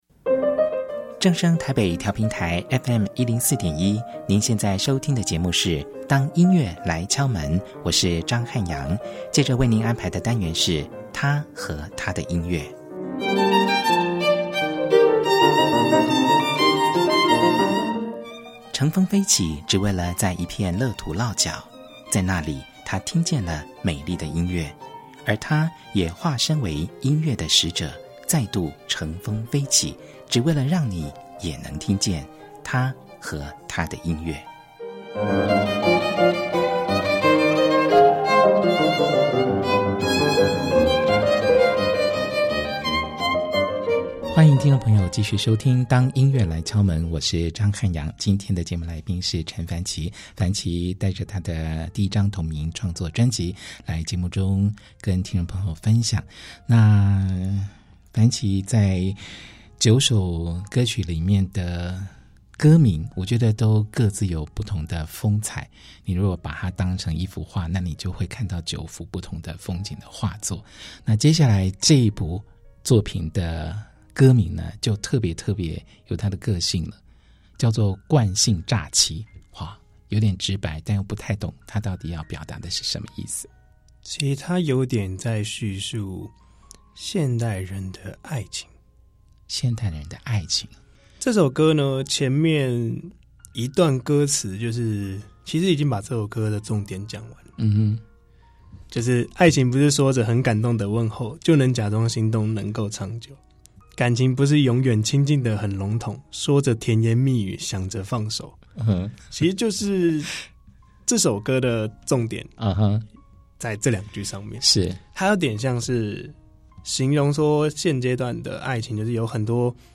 曲風多元，他以寬廣的聲線游刃有餘地駕馭。